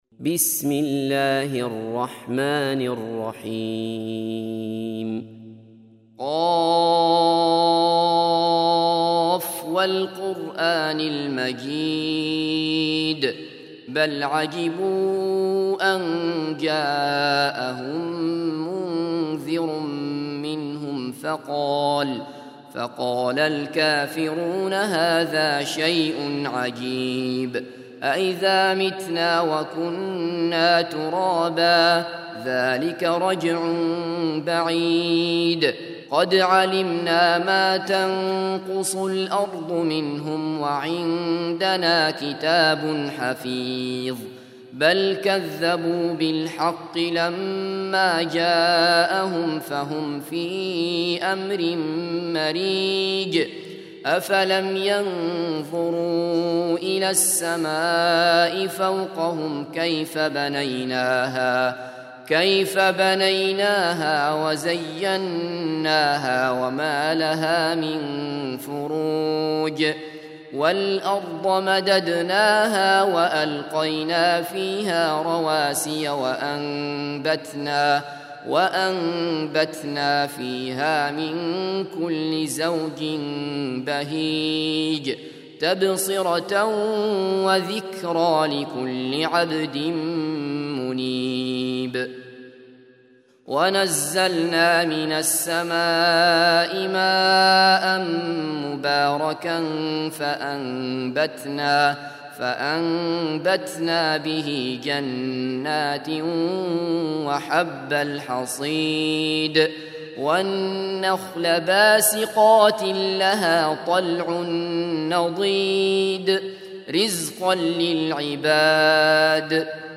50. Surah Q�f. سورة ق Audio Quran Tarteel Recitation
Surah Repeating تكرار السورة Download Surah حمّل السورة Reciting Murattalah Audio for 50.